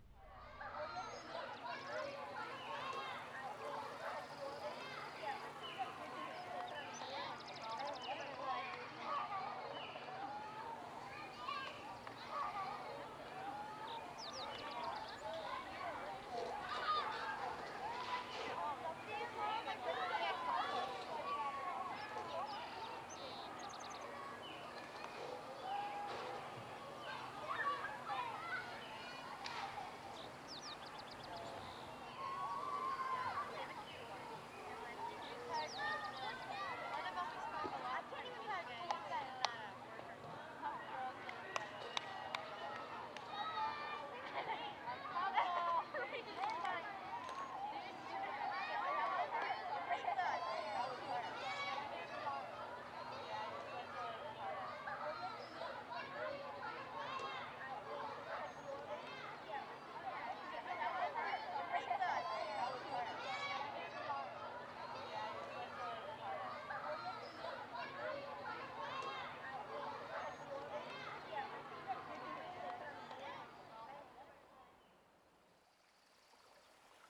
02_孤儿院走廊.wav